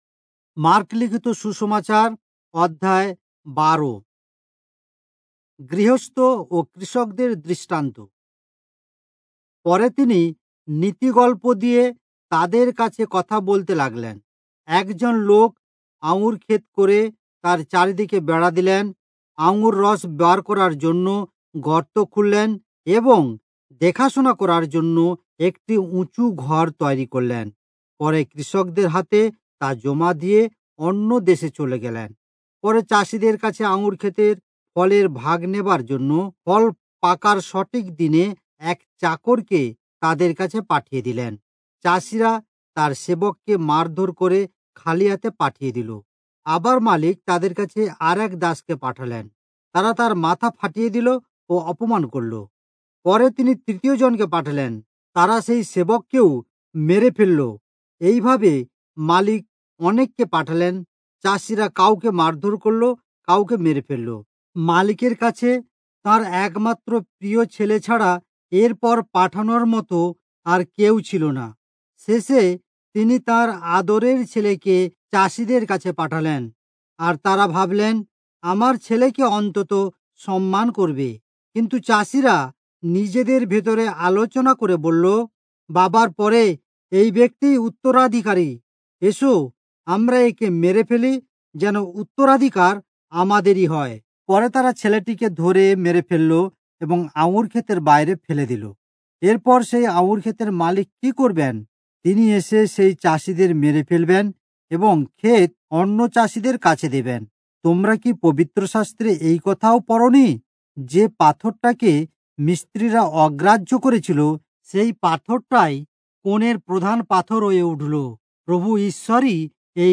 Bengali Audio Bible - Mark 15 in Irvbn bible version